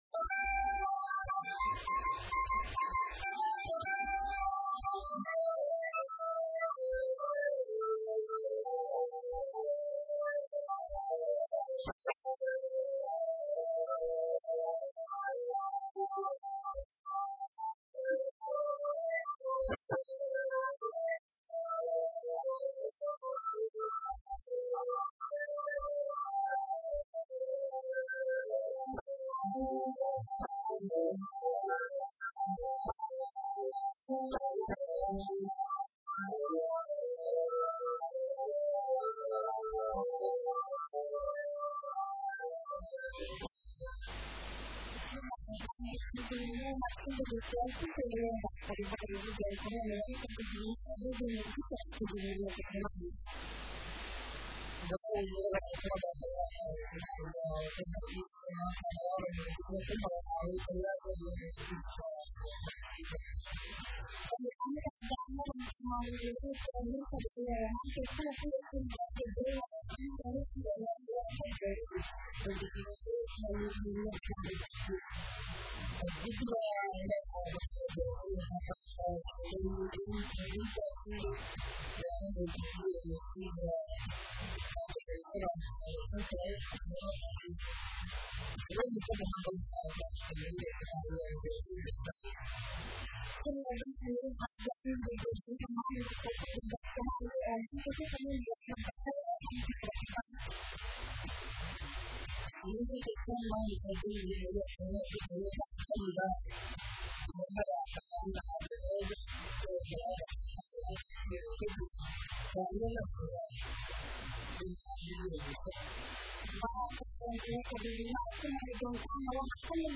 Dhageyso : Warka Duhur ee Radio Muqdisho